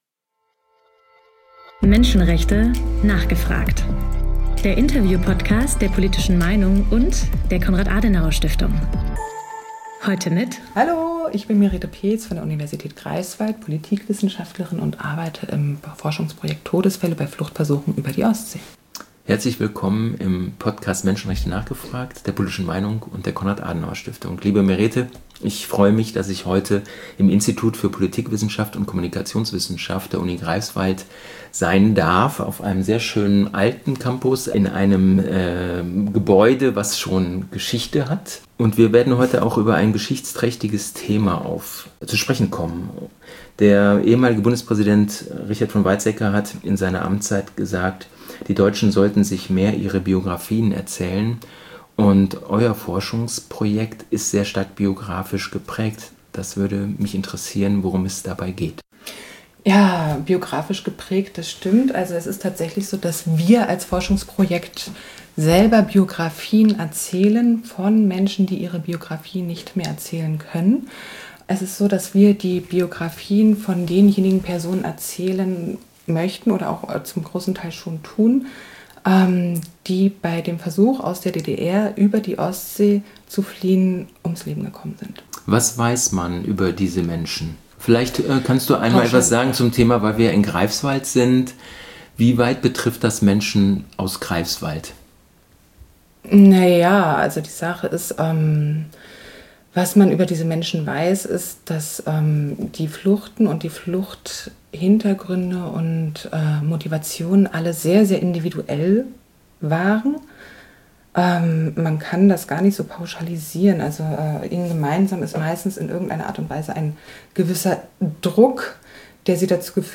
Menschenrechte: nachgefragt! - Der Interview-Podcast rund ums Thema Menschenrechte